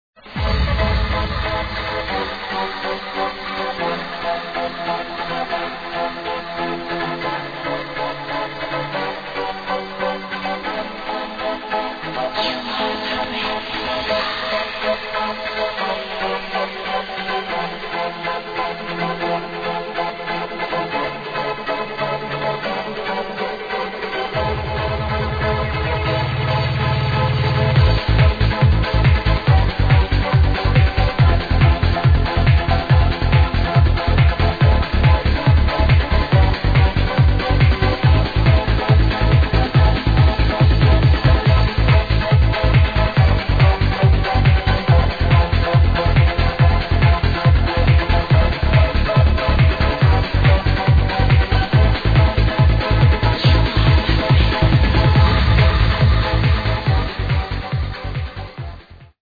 Nice melodic track, need some help...
very light trance track... really pretty tune so far